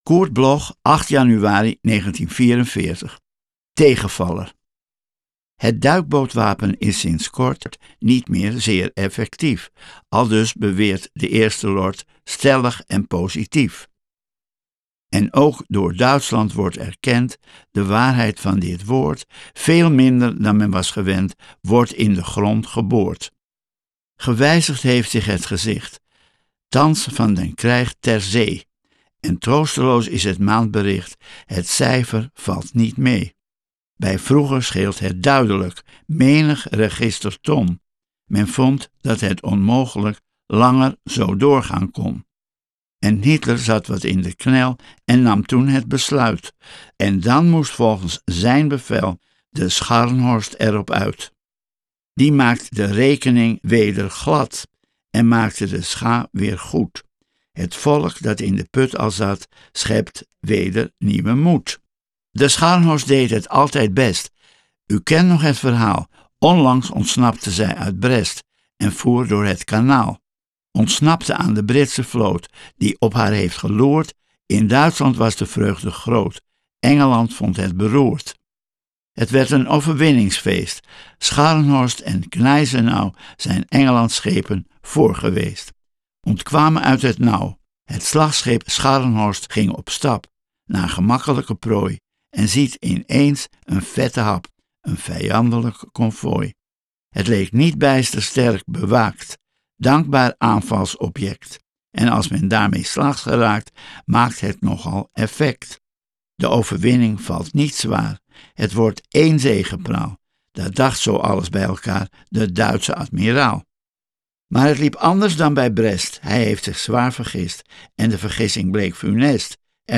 Aufnahme: Studio Levalo, Amsterdam · Bearbeitung: Kristen & Schmidt, Wiesbaden